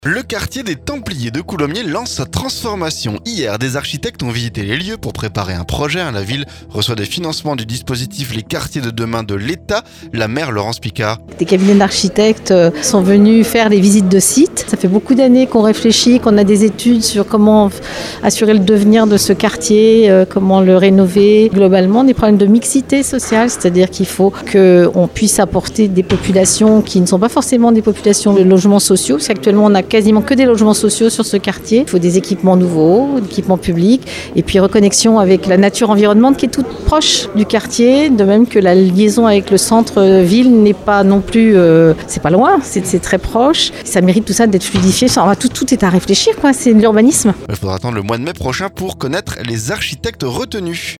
La maire Laurence Picard.